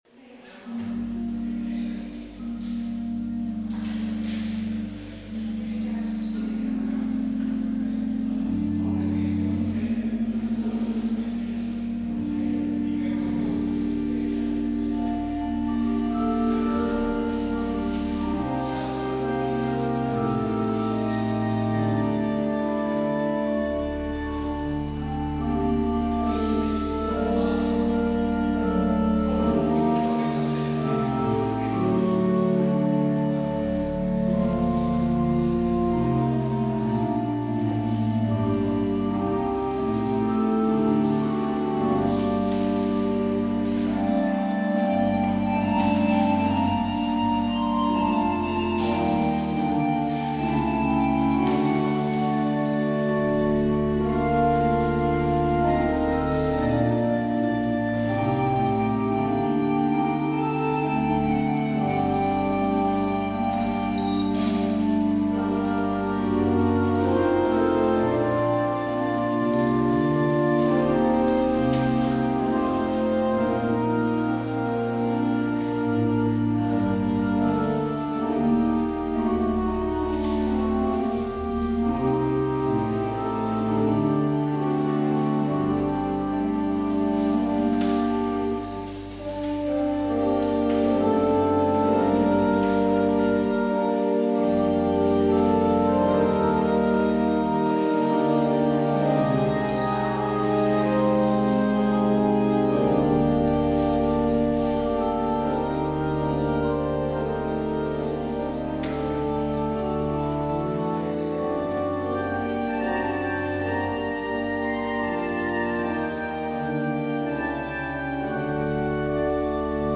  Luister deze kerkdienst terug
Als openingslied Psalm 139: 1, 2 en 3, Heer die mij ziet zoals ik ben. Het slotlied is lied 686: 1,2 en 3, De Geest des Heren heeft.